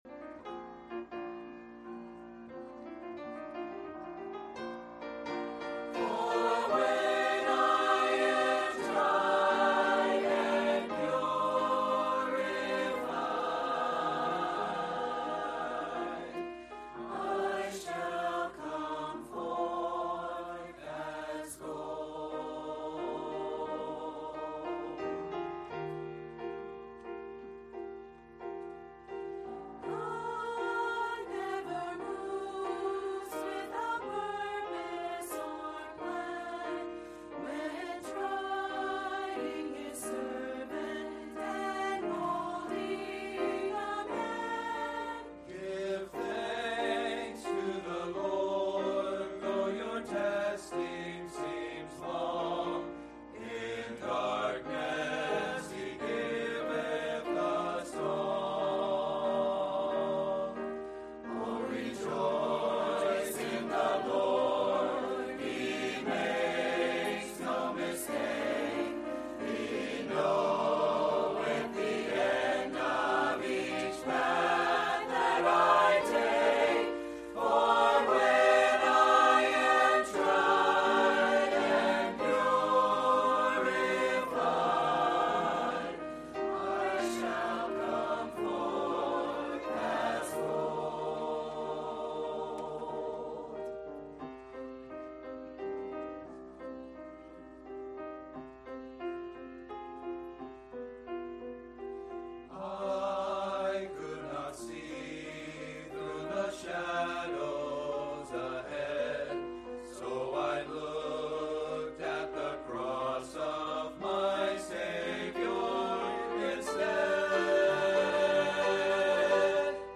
A message from the series "Focused on the Family."